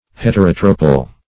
Search Result for " heterotropal" : The Collaborative International Dictionary of English v.0.48: Heterotropal \Het`er*ot"ro*pal\, Heterotropous \Het`er*ot"ro*pous\, a. [Gr.